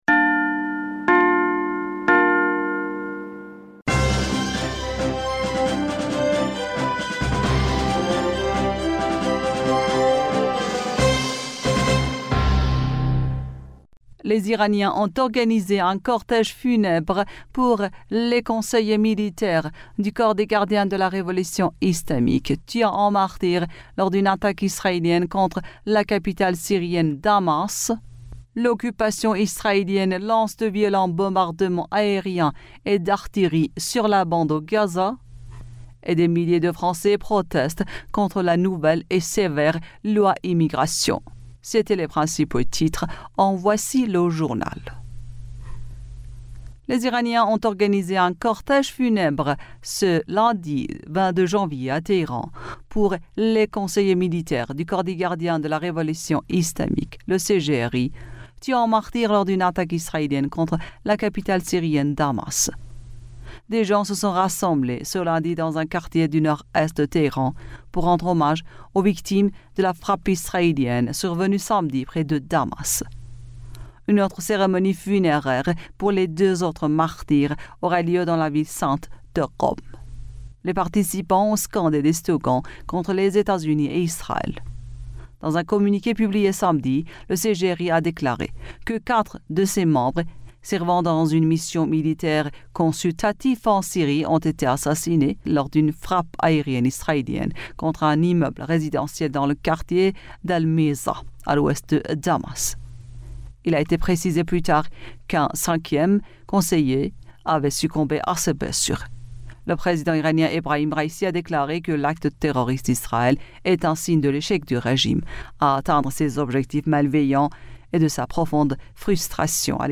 Bulletin d'information du 22 Janvier 2024